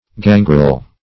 Gangrel \Gan"grel\, a.
gangrel.mp3